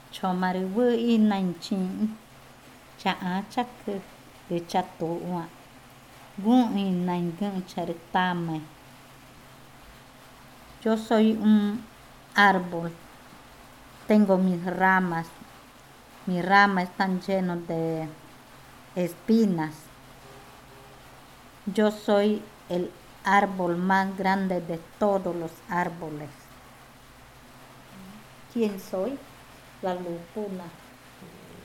Adivinanza 9. La lupuna
Cushillococha